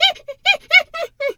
pgs/Assets/Audio/Animal_Impersonations/hyena_laugh_short_06.wav at master
hyena_laugh_short_06.wav